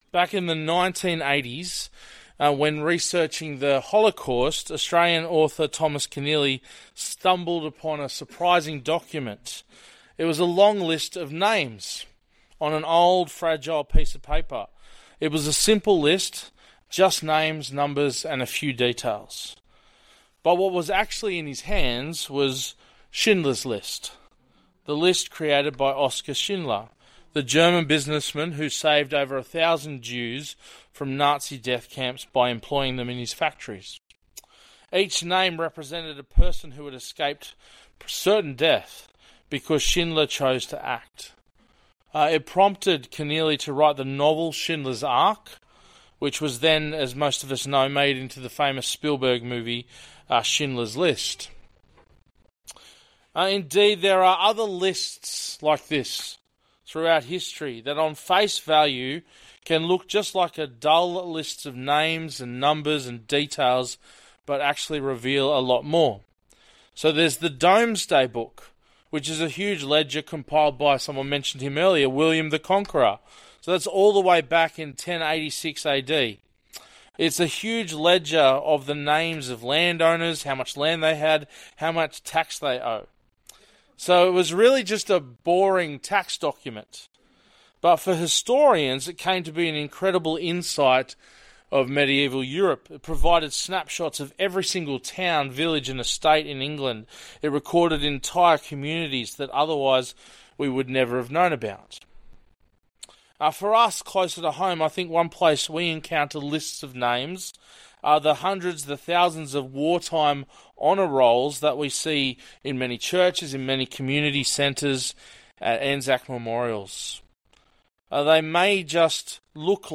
Bible Talks | Bairnsdale Baptist Church